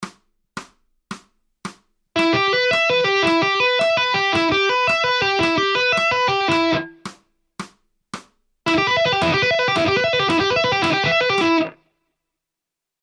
JASON BECKER ARPEGGI IN TAPPING